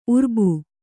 ♪ urbu